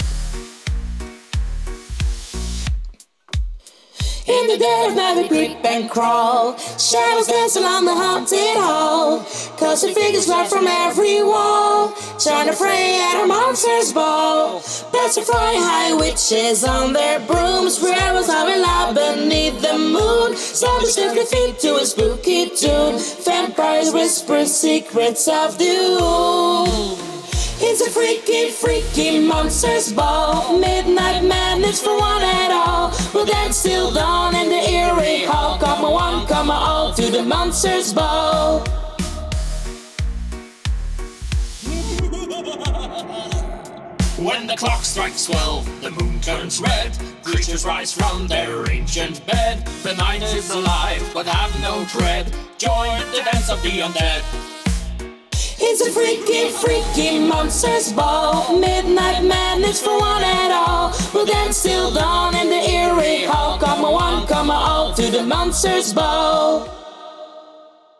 Sopraan